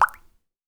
drop.wav